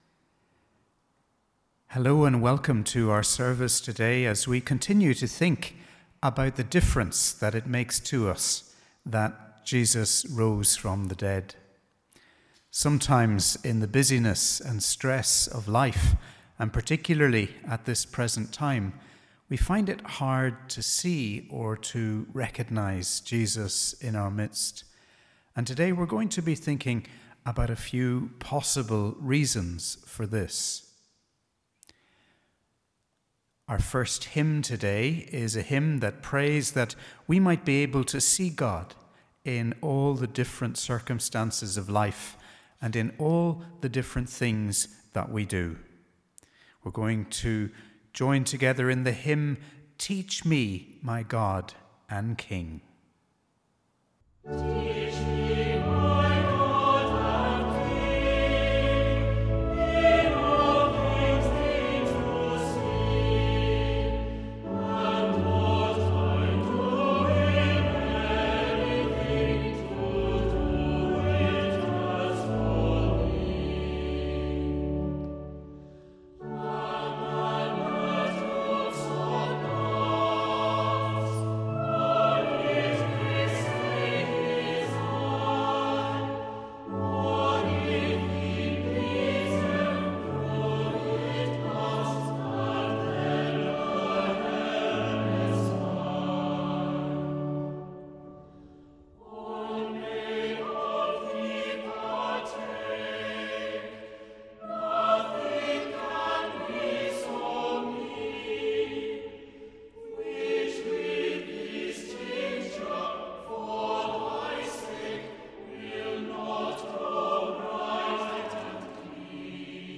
Welcome to our audio service on this third Sunday of Easter. Today, we think of Jesus’ appearance to two of his disciples as they walked along the road to Emmaus.